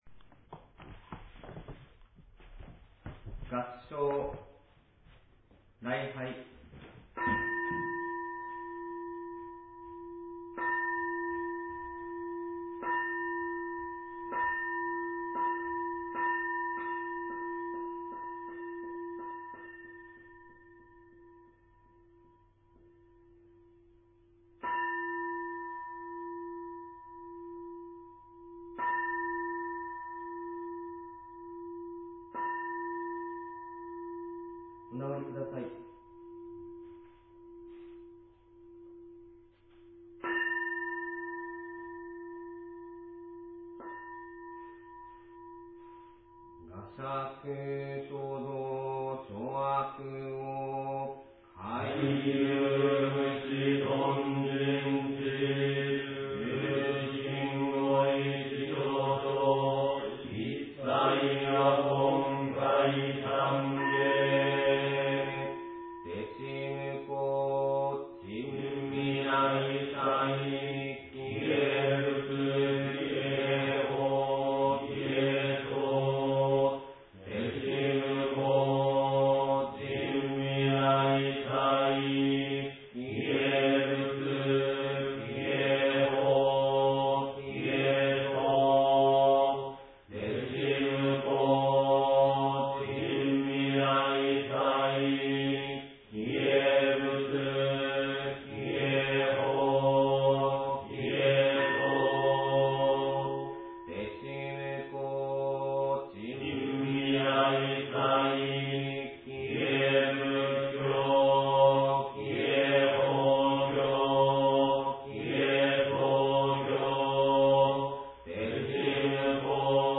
仏前勤行次第音源
001仏前勤行次第音源.mp3